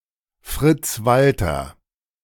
Friedrich "Fritz" Walter (German: [ˈfʁiːdʁɪç fʁɪts ˈvaltɐ]